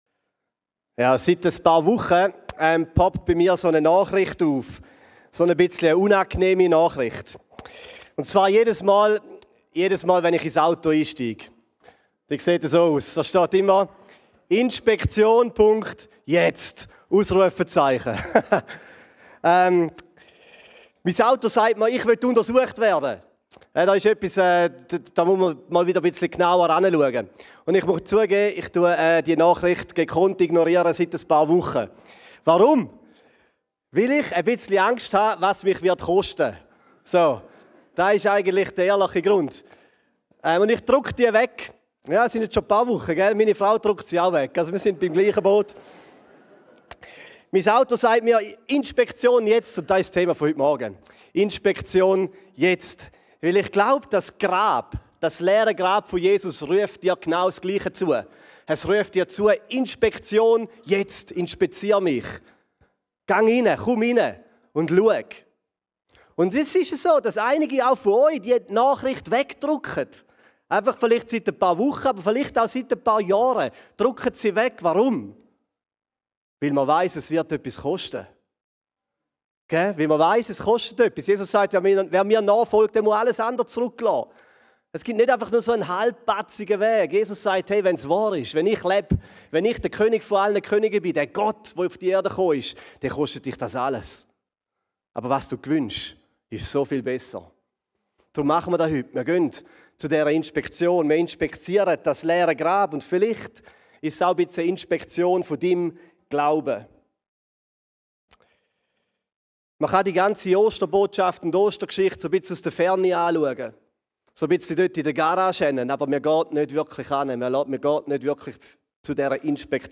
Advent – Predigtserie
Diese Osterpredigt nimmt uns mit auf eine ganz besondere Untersuchung. Sie zeigt auf, dass ein leeres Grab allein – so historisch belegt Jesus auch sein mag – noch keinen lebendigen Glauben macht.